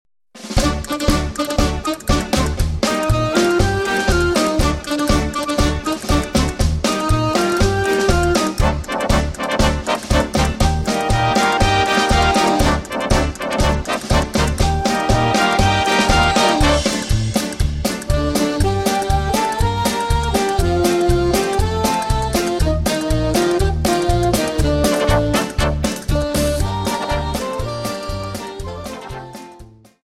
Paso Doble 60 Song